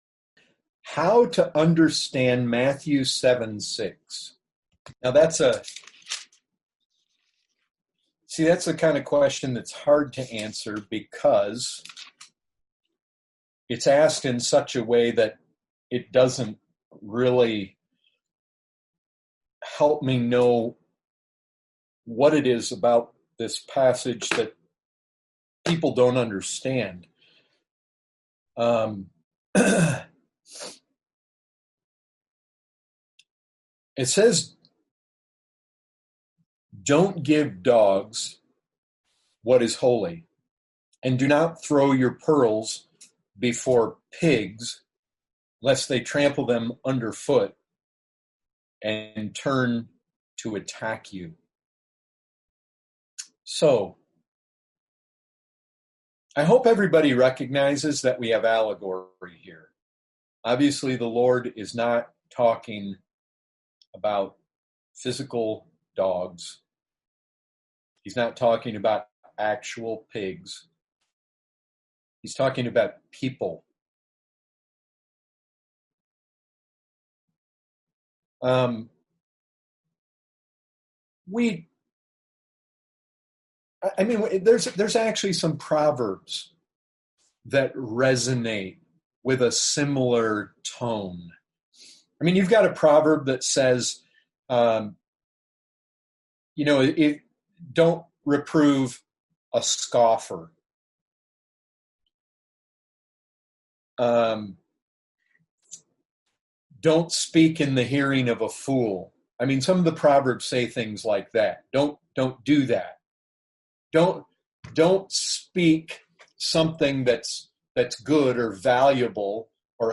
Category: Questions & Answers